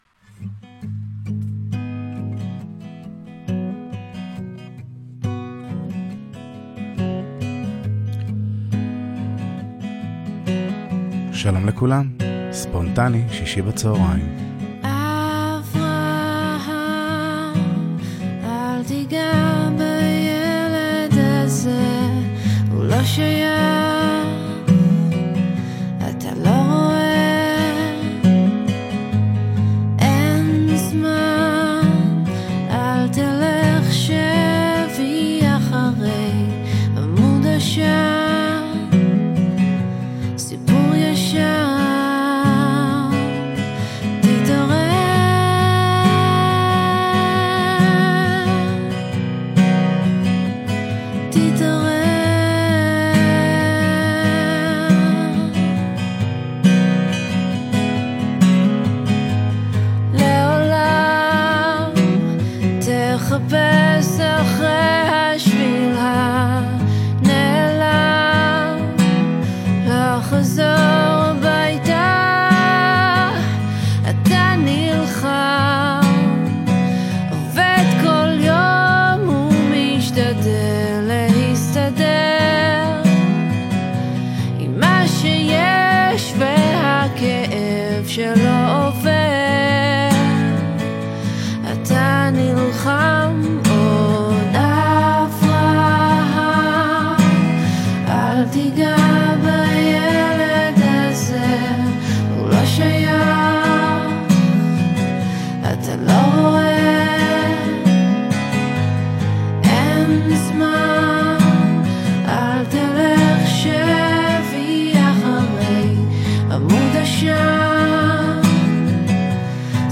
ככה, בין כסה לעשור, דקה אחרי יום השואה שעה מהורהרת של שירים ישראליים ריפוי לנפש ולגוף שבת שלום ומבורך 🙂